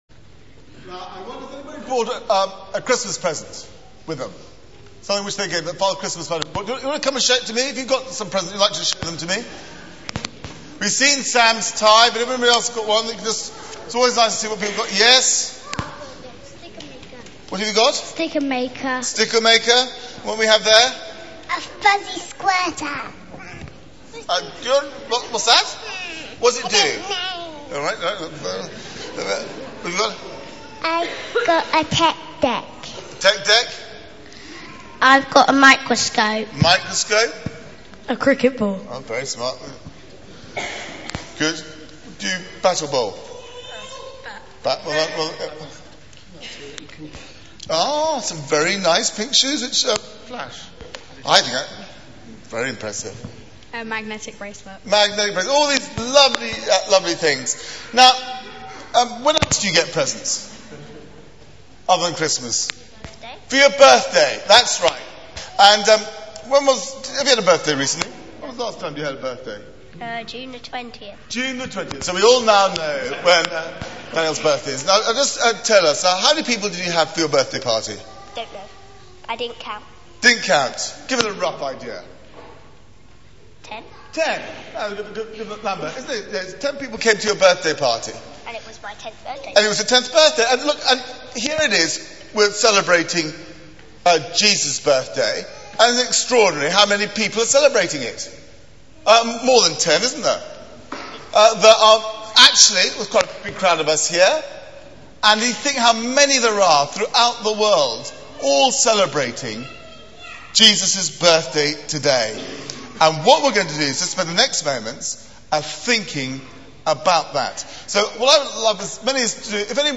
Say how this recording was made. Media for 9:15am Service on Thu 25th Dec 2008 09:15 Speaker: Passage: John 1: 1-14 Series: Christmas Morning Service Theme: Talk Search the media library There are recordings here going back several years.